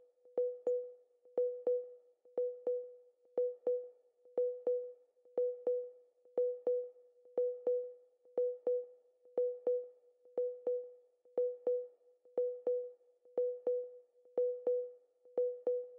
合成器打击乐
描述：Ableton_Operator_Delay_Reverb120Bpm C＃minor
Tag: 电子 打击乐